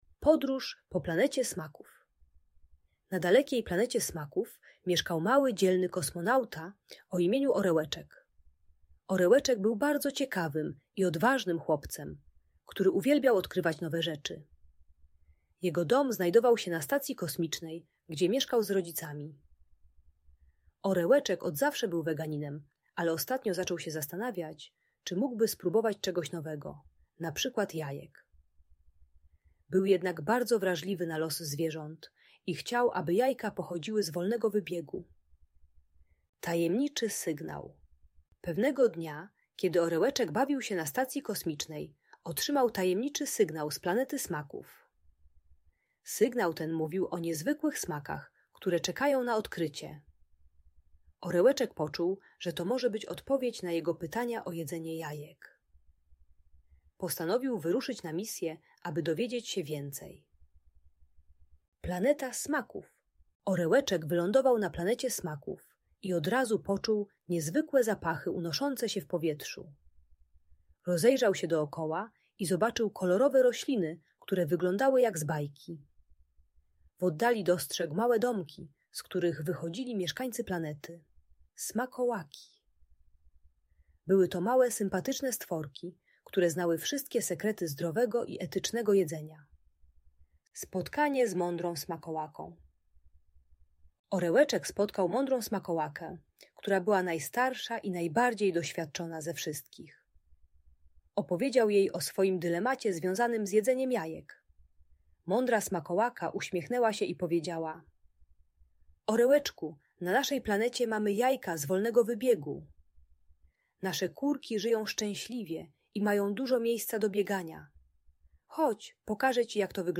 Audiobajka o problemach z jedzeniem dla przedszkolaków.